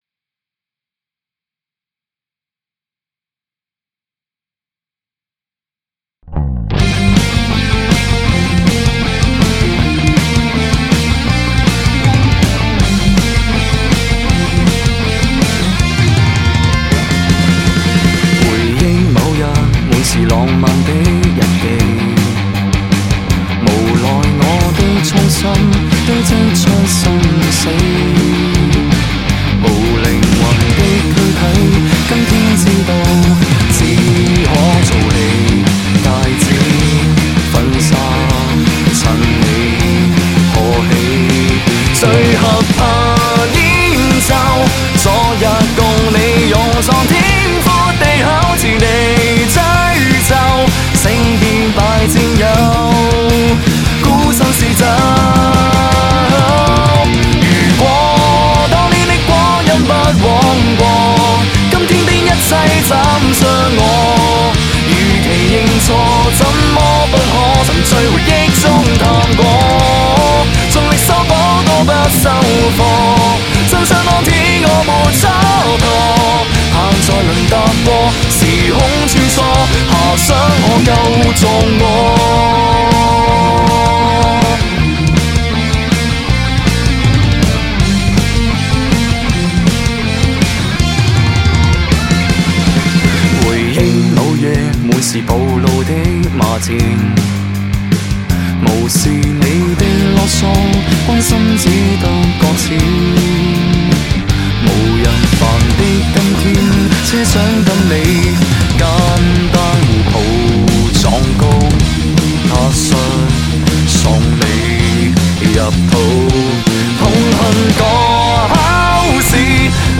It is meaningless to fool yourself into thinking that you can relive the past. Through my powerful Rock music, I hope to pep up those who lost their incentive to regain their momentum in life.
Regret A sound about the negative emotions of life.